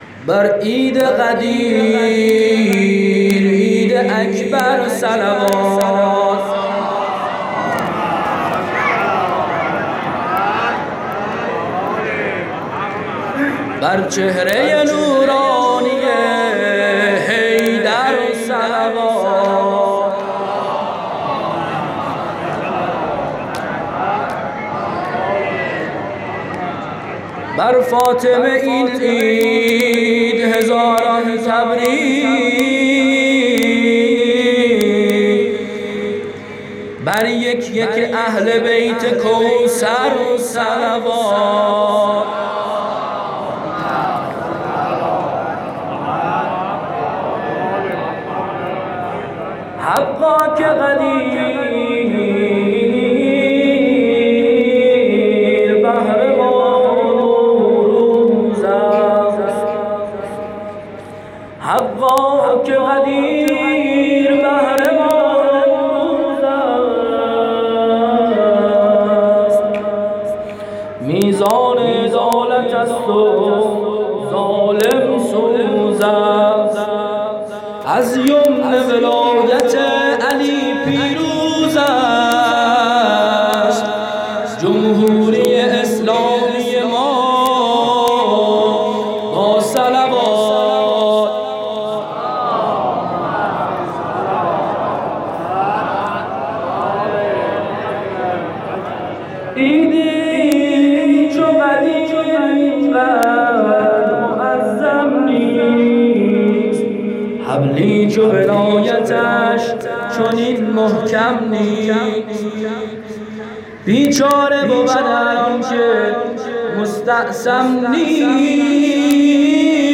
گزارش صوتی عید غدیر ۹۸